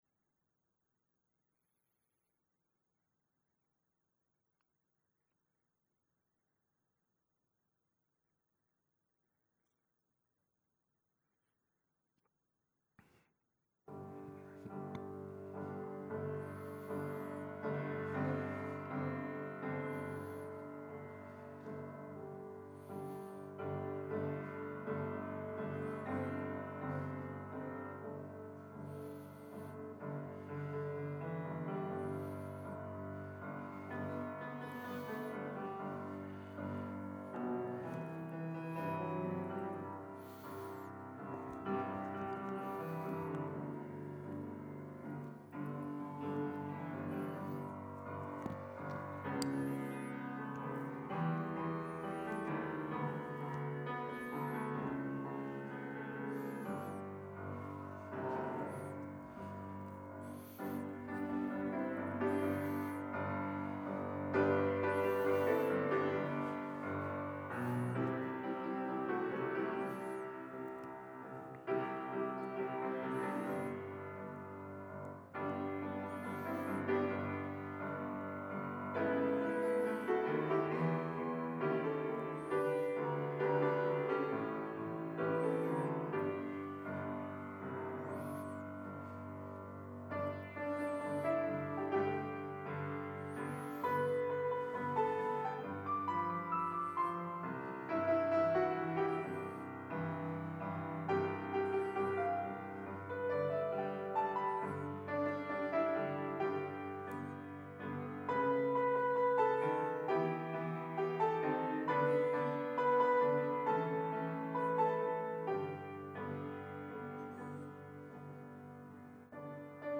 Sermon 3rd Sunday after Epiphany